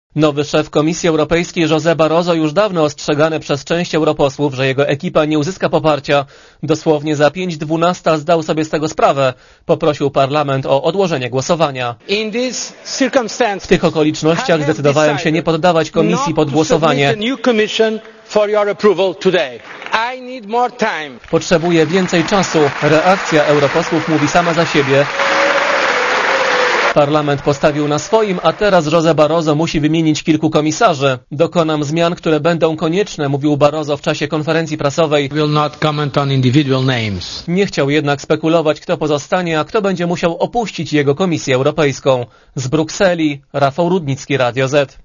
Korespondencja z Brukseli Doszedłem do wniosku, że gdyby głosowanie odbyło się dzisiaj, wynik nie byłby pozytywny dla instytucji UE i projektu europejskiego - wyjaśnił.